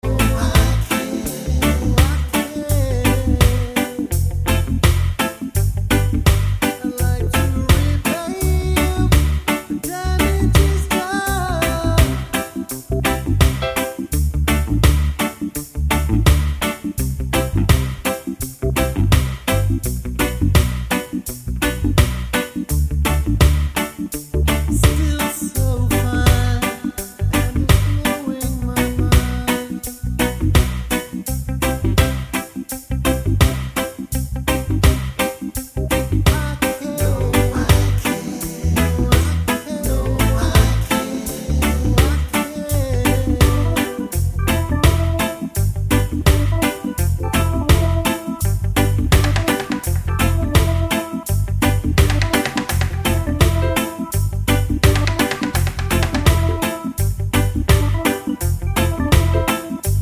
no Backing Vocals Reggae 4:12 Buy £1.50